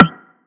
DDW4 PERC 1.wav